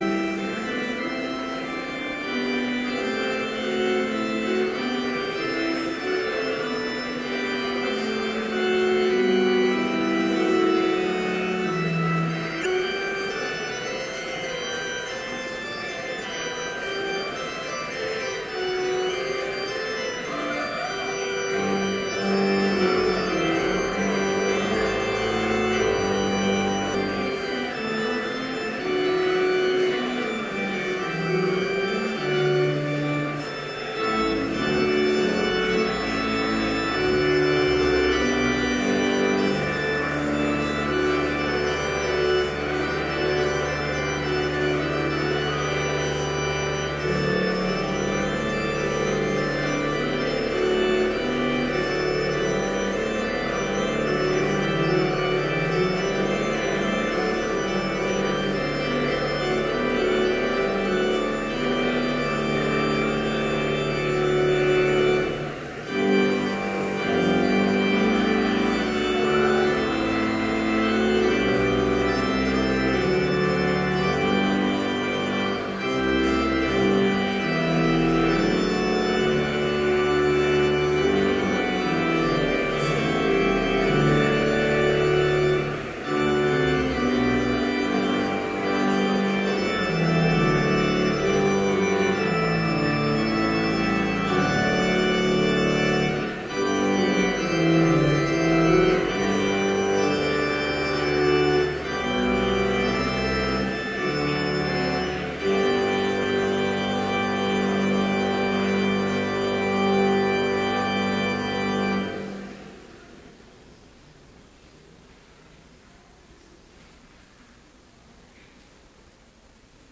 Complete service audio for Chapel - January 14, 2014